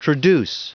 Prononciation du mot traduce en anglais (fichier audio)
Prononciation du mot : traduce